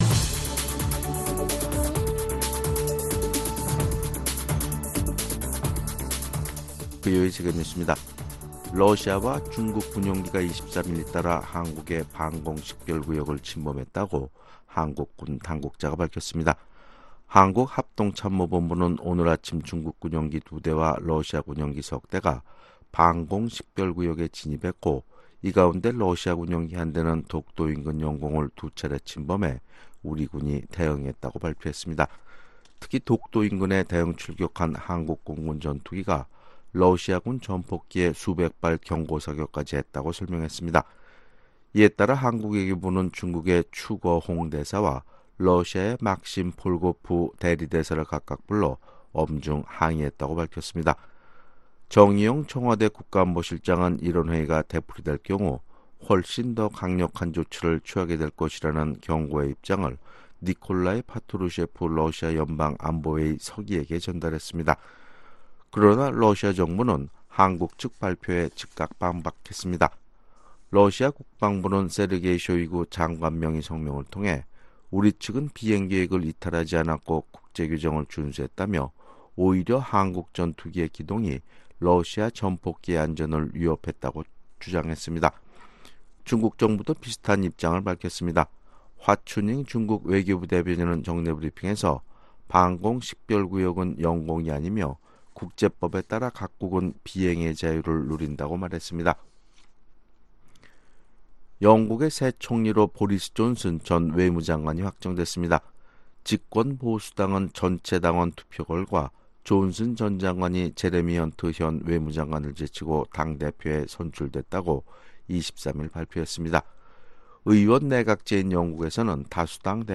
VOA 한국어 아침 뉴스 프로그램 '워싱턴 뉴스 광장' 2019년 7월 24일 방송입니다. 러시아 군용기가 한국 영공을 침범해 한국 공군 전투기가 경고 사격에 나서는 사건이 일어났습니다. 중국의 통신장비 업체 화웨이가 비밀리에 북한의 이동통신망 구축을 도왔다는 미 언론의 보도가 나온 가운데, 전문가들은 대북 제재 위반 여부에 신중한 반응을 보이고 있습니다.